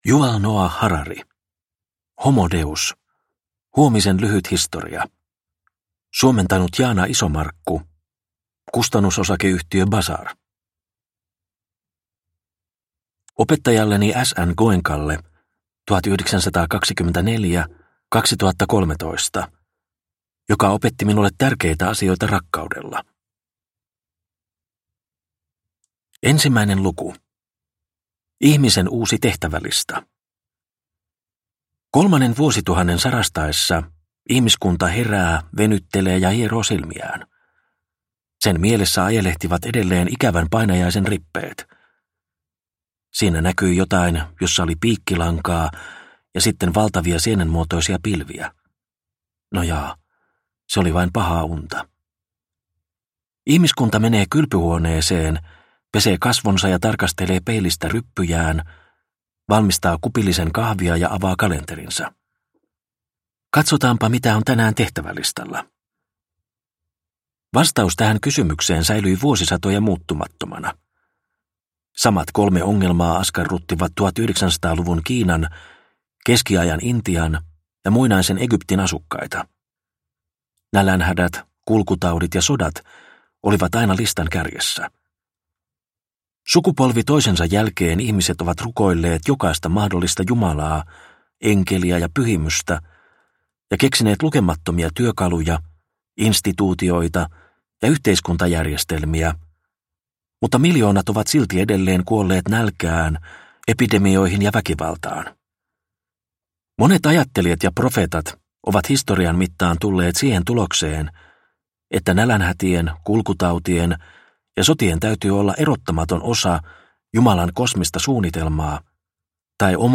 Homo deus – Ljudbok – Laddas ner